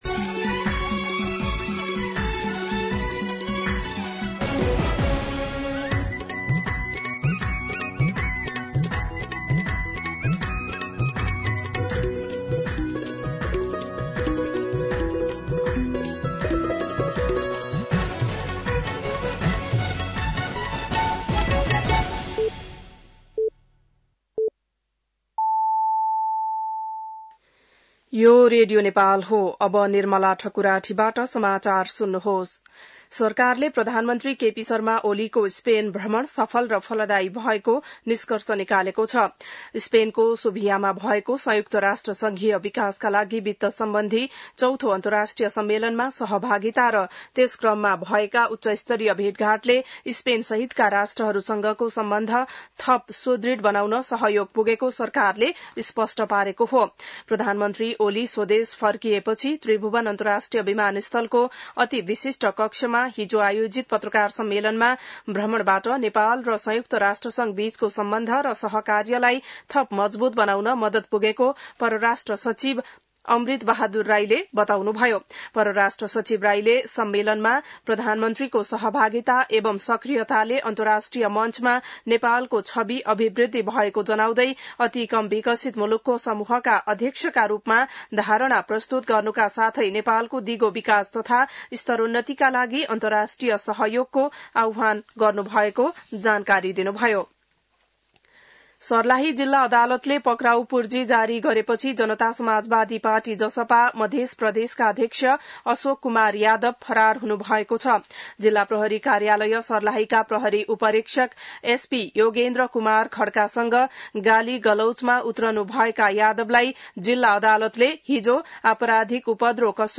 An online outlet of Nepal's national radio broadcaster
बिहान ११ बजेको नेपाली समाचार : २१ असार , २०८२